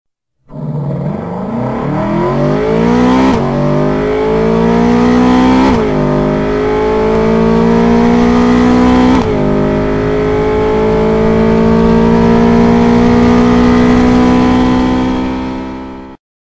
California引擎换挡轰鸣 California引擎换挡轰鸣
相比F430，California显得温柔时尚了许多，但毕竟拥有纯正的基因，也一样有着法拉利式的咆哮。
突出声效的同时，也表现驾驶者高超的换挡技术。